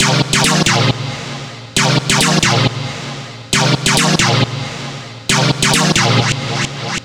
Stab 136-BPM 3 C.wav